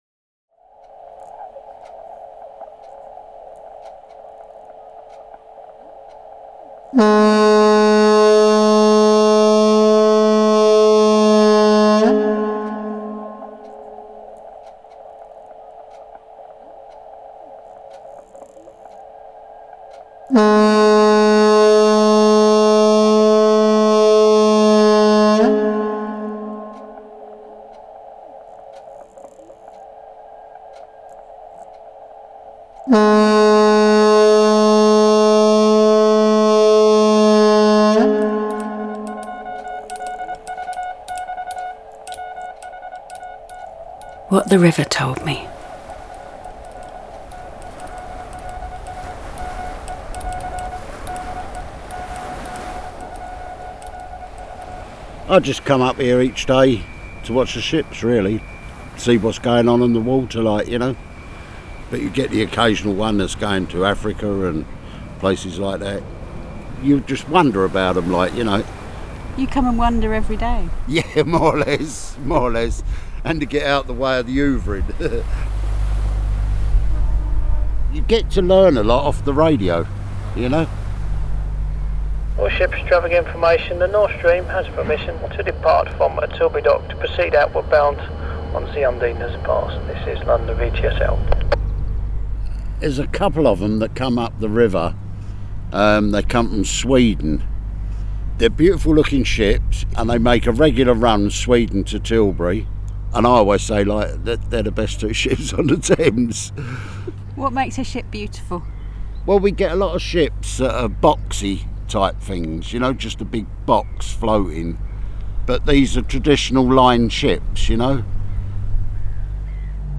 A retired man who once dreamed of going to sea eavesdrops on the radios of ships floating down the river. An older lady describes the excitement of running across the beach for a swim. A widower stops to talk to his dead wife.